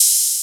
Closed Hats
15_TrapHats_SP_10.wav